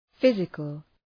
{‘fızıkəl}